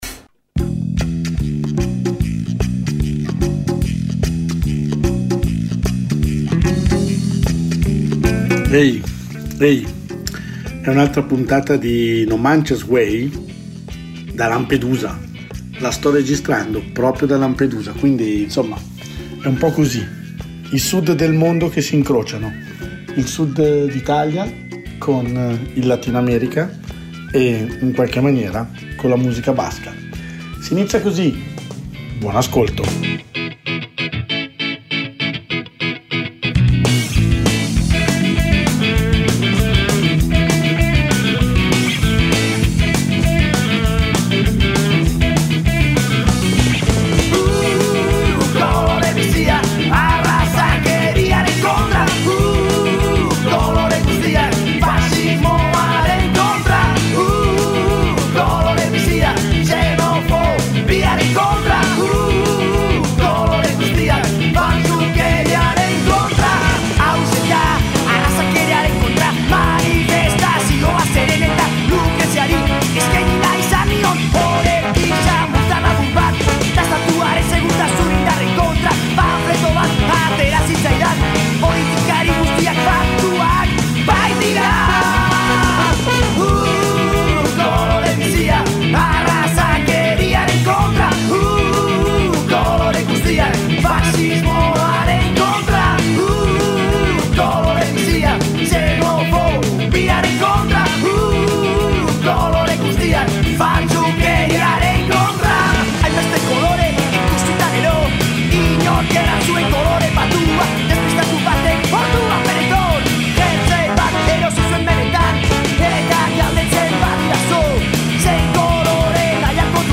Un viaggio musicale dentro le culture latino americane.